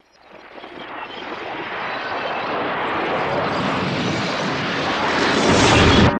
Reversed Stinger Book of the Dead Fly Up To Camera